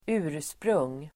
Uttal: [²'u:r_sprung:]